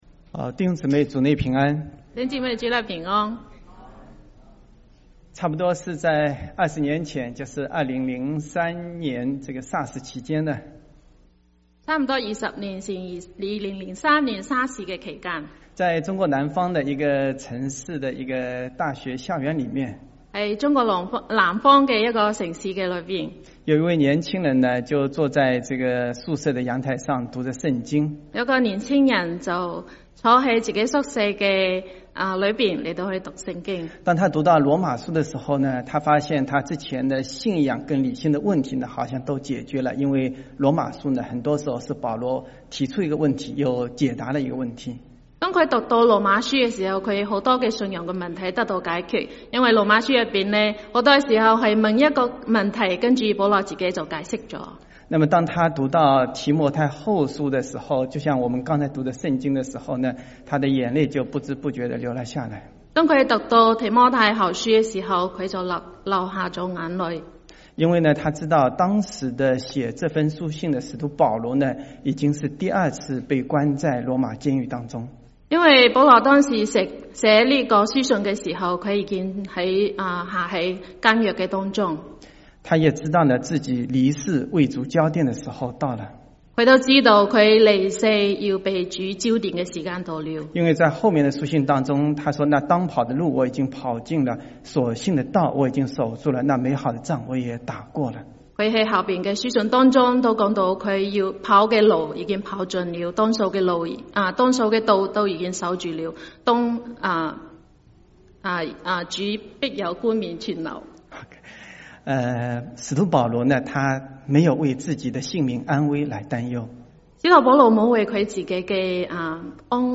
1/12/2025 國粵語聯合崇拜: 「在恩典上剛強起來」